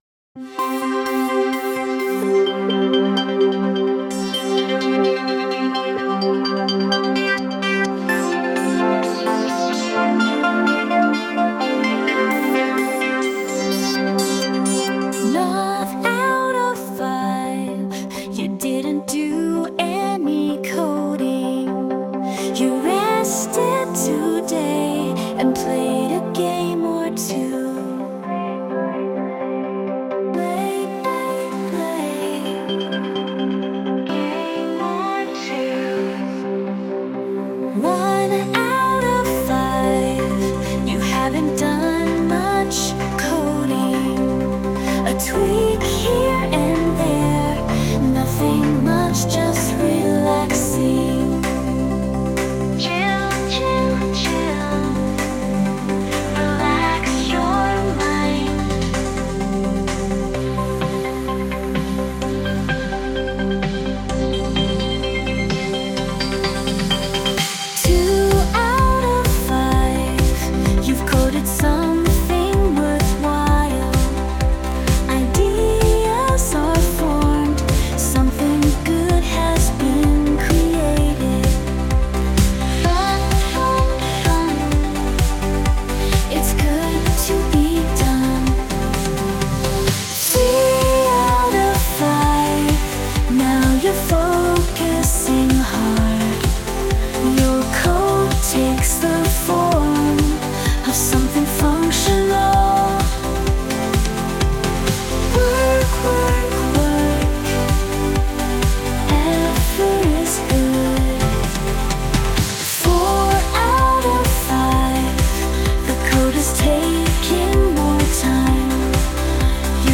This is the new v4.0 Suno Engine.
Sung by Suno